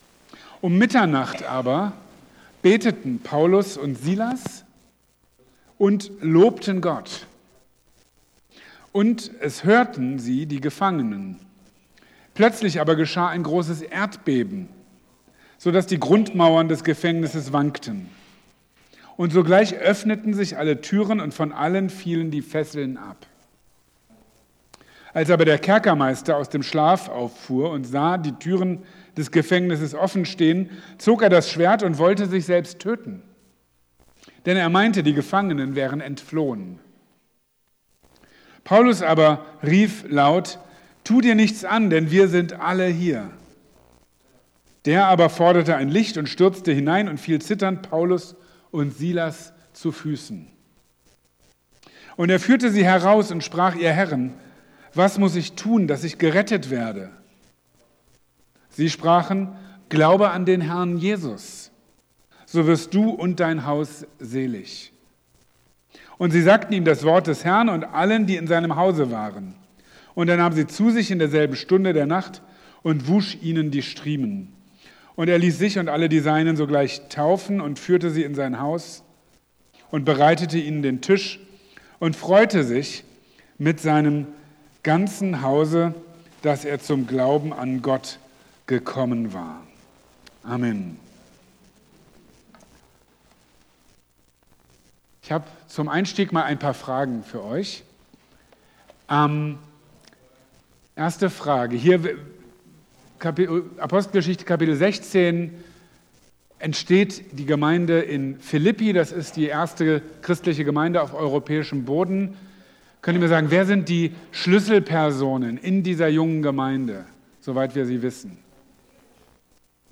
Predigten aus der Anskar-Kirche Marburg